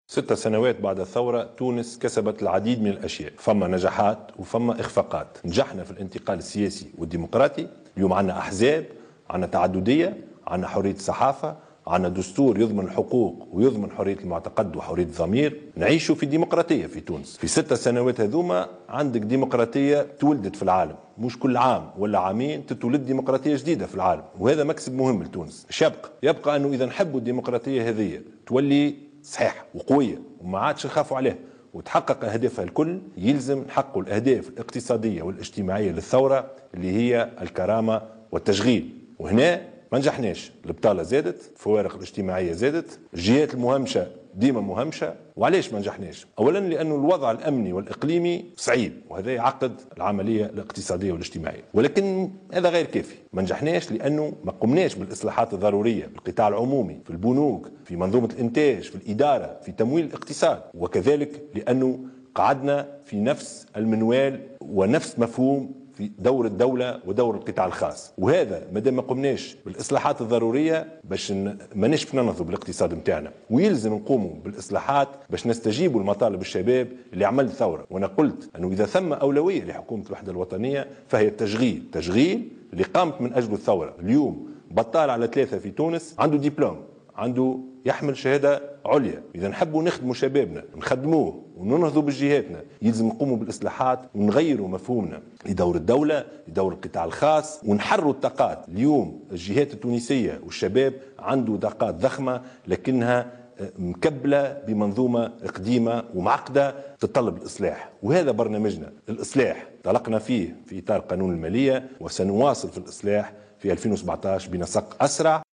أقر رئيس الحكومة يوسف الشاهد في كلمة له بمناسبة ذكرى الثورة عبر القناة الوطنية الأولى، بفشل الحكومات المتعاقبة بعد 14 جانفي 2011 في إيجاد حل لأهم مطالب الثورة وهو التشغيل مشيرا الذي يظل أحد أهم أولويات حكومة الوحدة الوطنية.